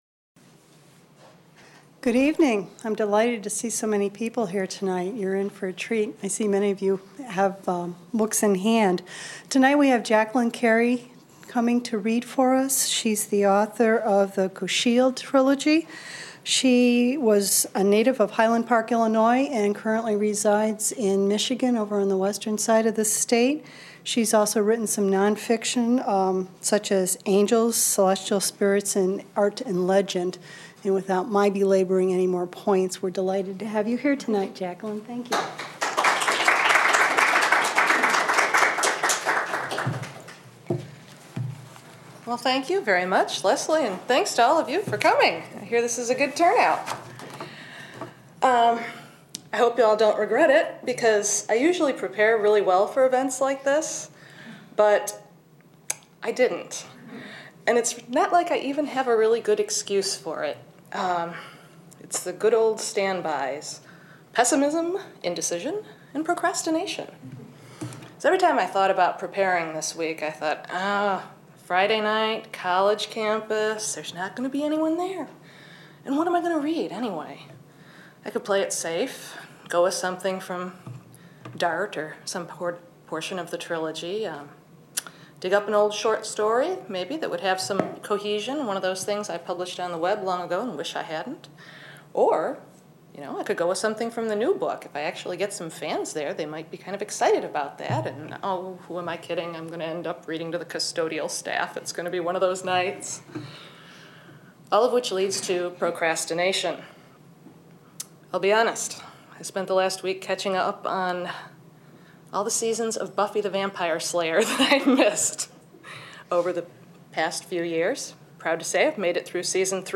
Essayist and fantasy writer Jacqueline Carey reads excerpts from her book "Godslayer" at the Michigan Writers Series